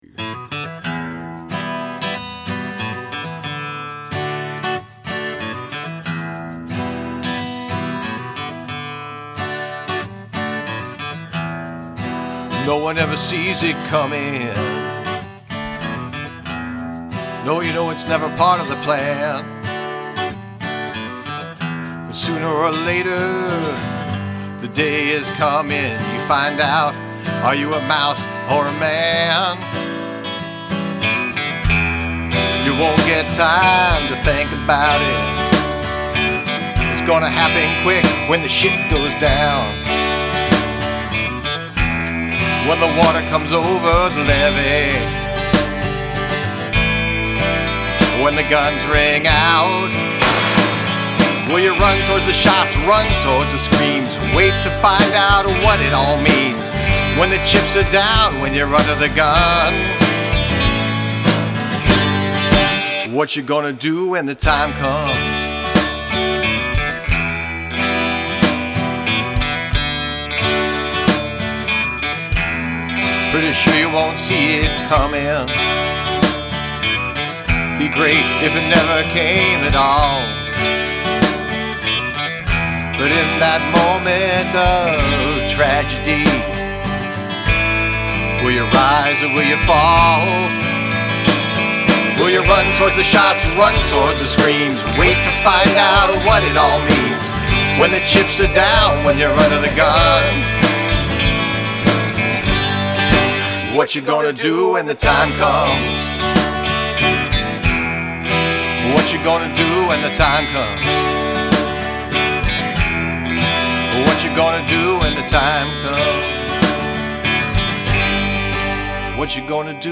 Use of the sound of gunfire
Love your vocals! Acoustic sounds great as usual.
Nice riffs and 12 string.
Very bright mix.